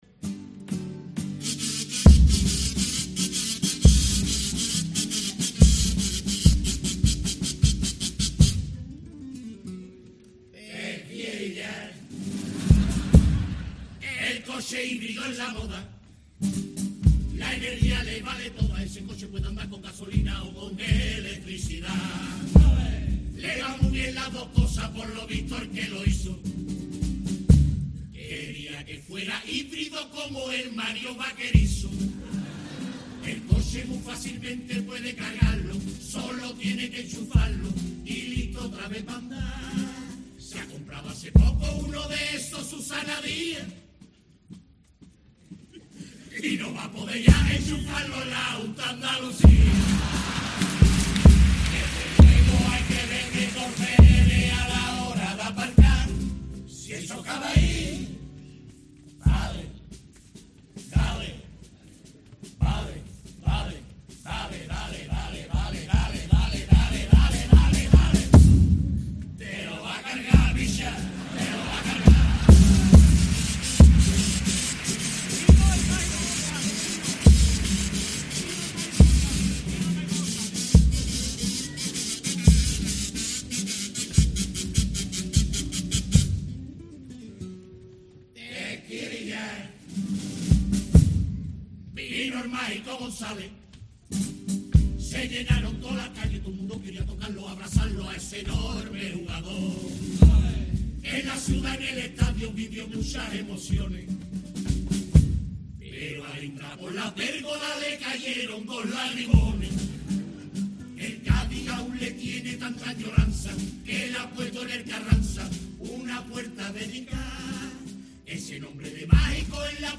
Los mejores cuplés de la chirigotas de la final